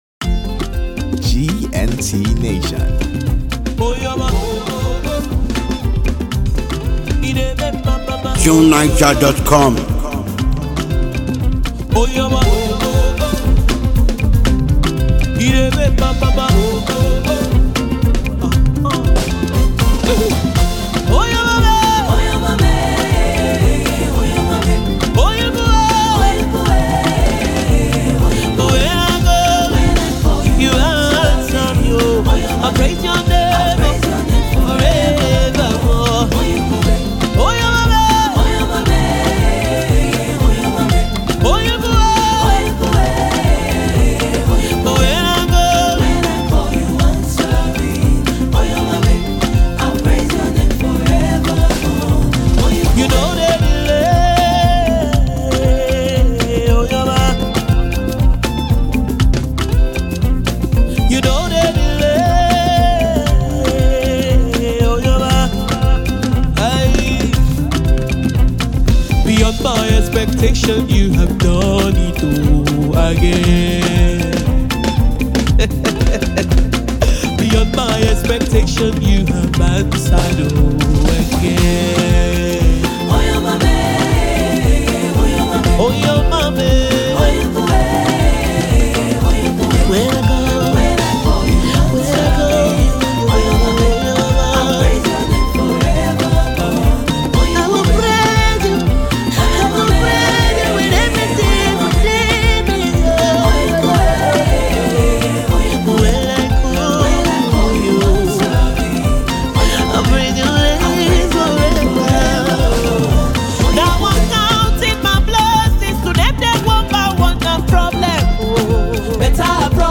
Nigerian gospel singer and songwriter